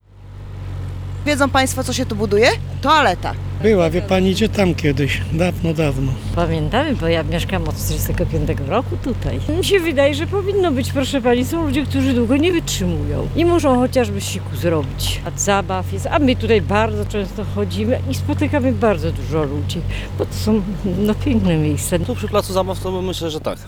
kopara.mp3